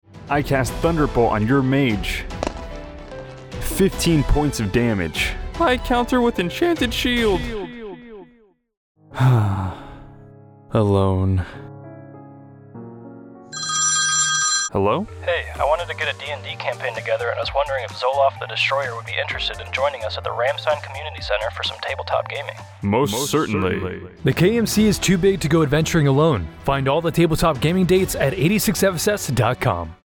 Radio Spot - Table Top Gaming